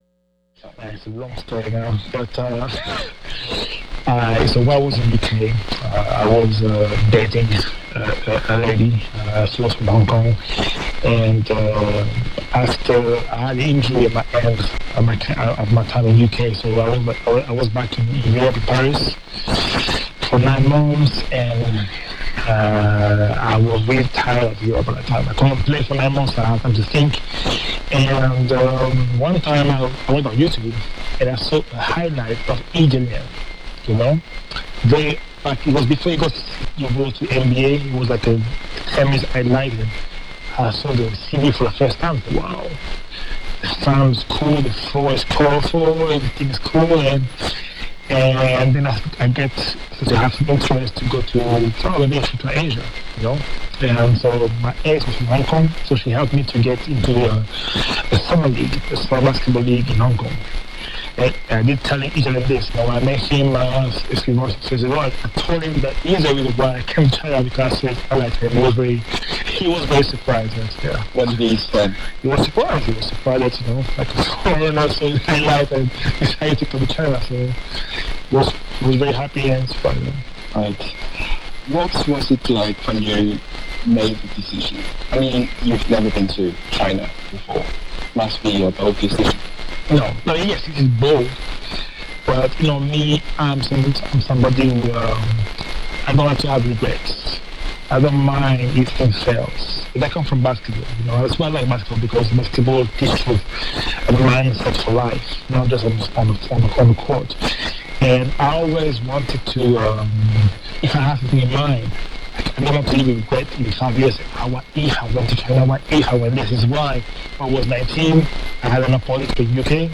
LOC: CHINA, URUMQI HUTUBI, 44.08 N 86.53 E